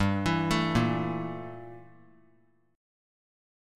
Listen to Gm6add9 strummed